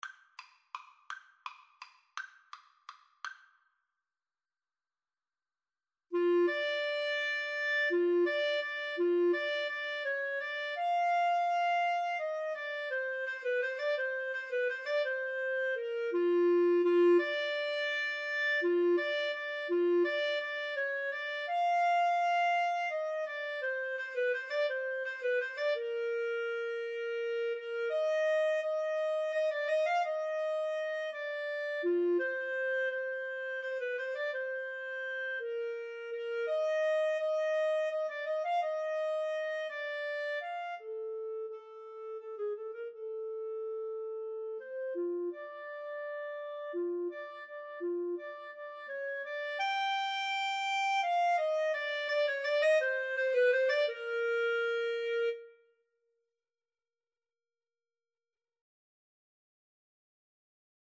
3/4 (View more 3/4 Music)
Tempo di valse =168
Clarinet Duet  (View more Easy Clarinet Duet Music)
Classical (View more Classical Clarinet Duet Music)